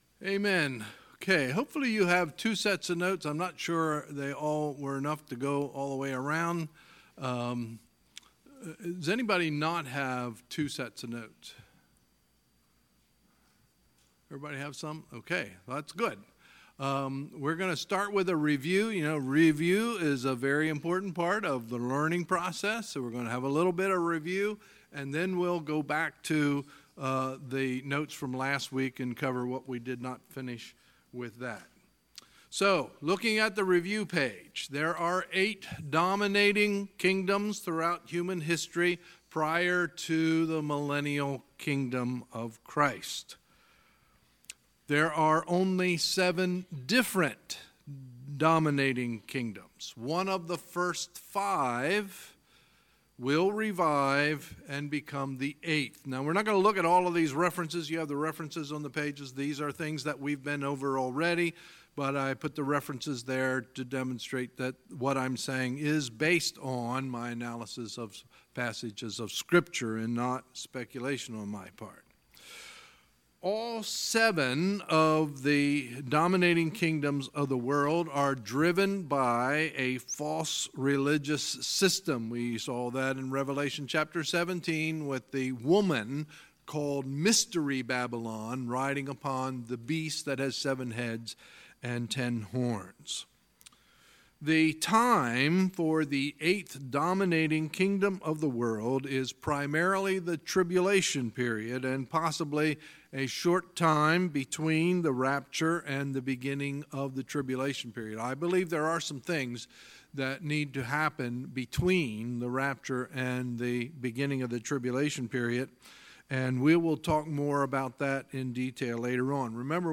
Sunday, August 5, 2018 – Sunday Evening Service